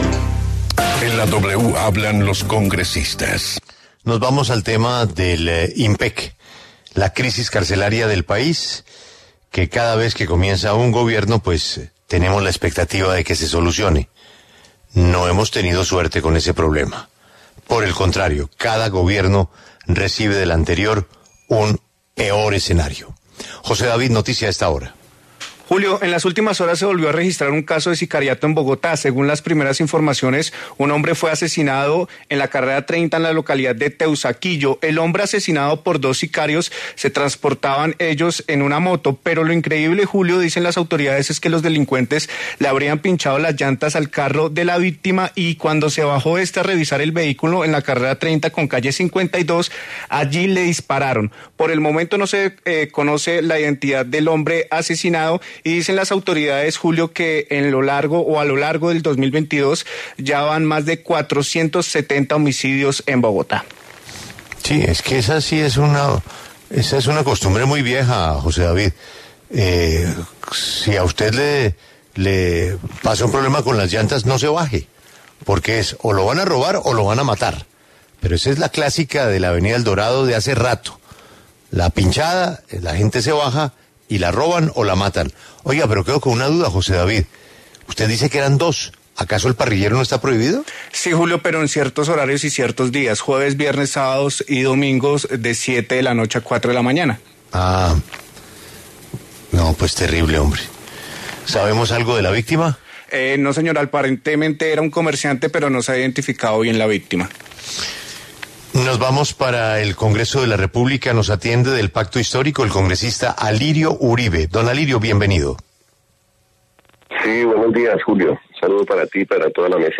Alirio Uribe, del Pacto Histórico y Miguel Uribe, del Centro Democrático, conversaron en La W sobre la propuesta de una comisión accidental para buscar alternativas que permitan excarcelar a los jóvenes detenidos en el marco de las protestas de 2021, muchos de ellos de la Primera Línea.